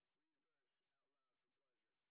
sp04_white_snr30.wav